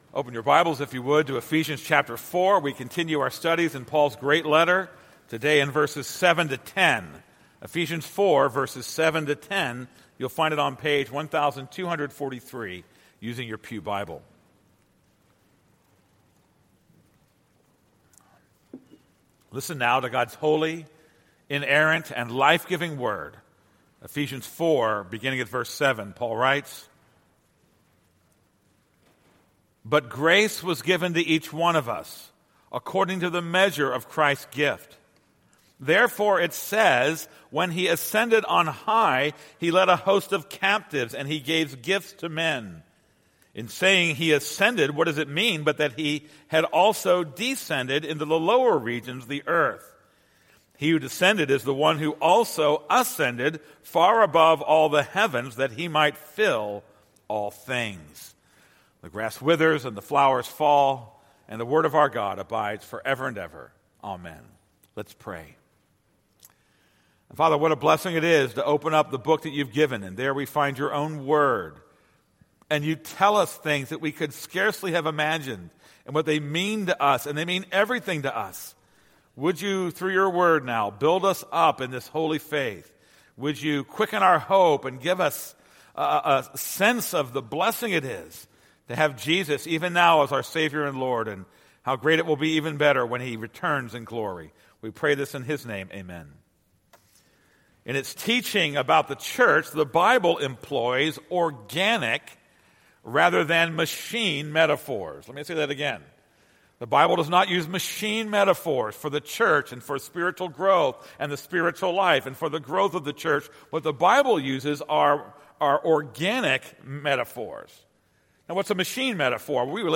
This is a sermon on Ephesians 4:7-10.